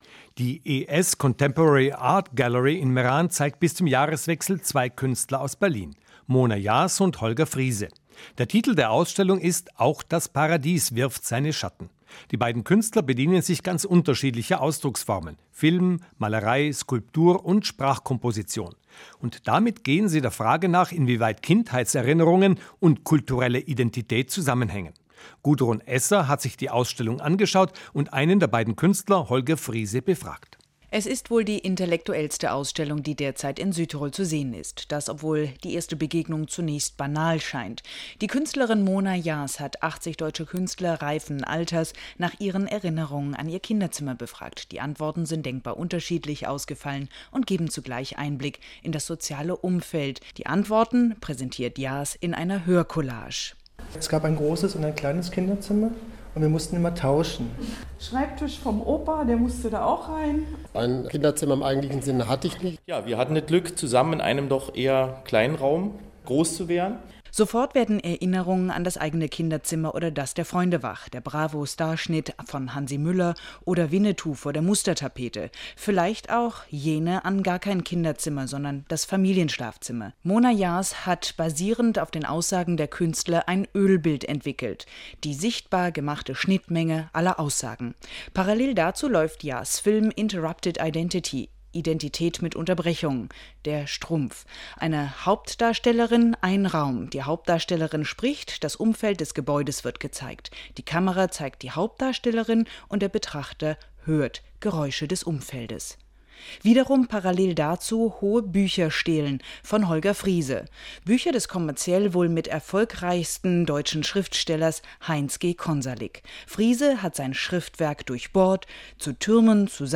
Feature RAI Bozen